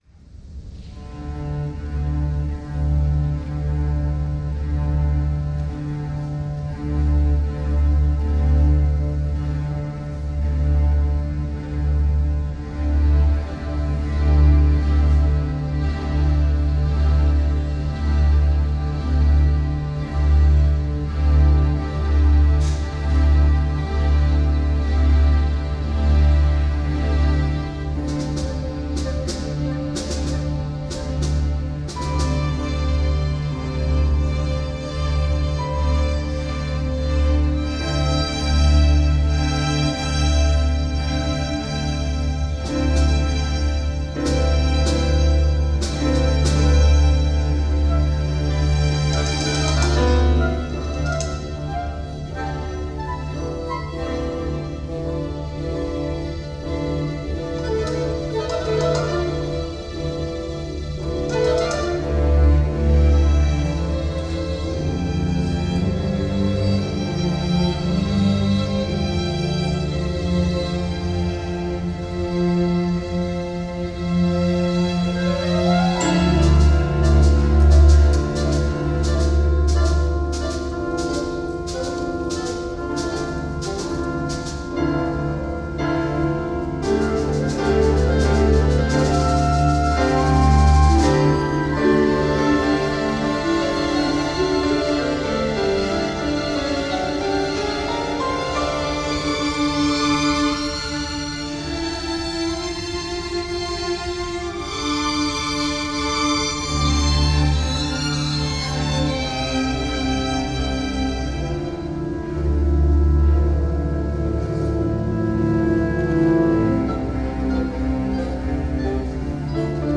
Instrumentation: chamber orchestra